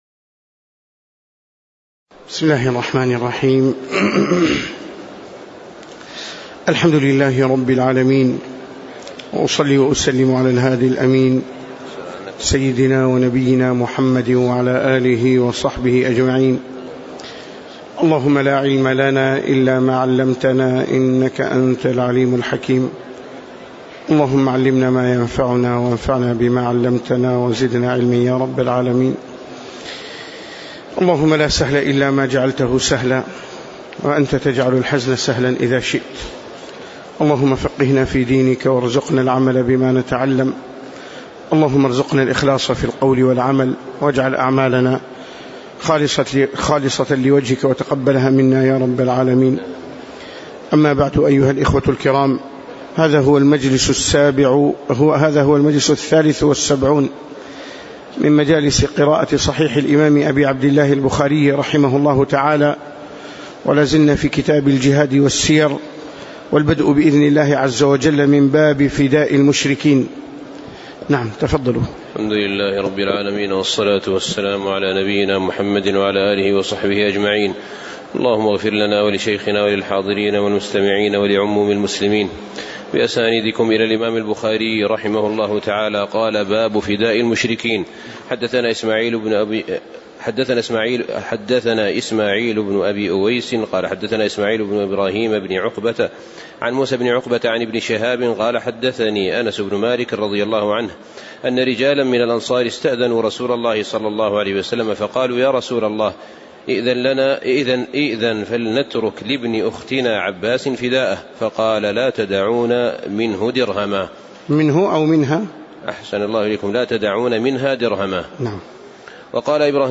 تاريخ النشر ٢ جمادى الآخرة ١٤٣٨ هـ المكان: المسجد النبوي الشيخ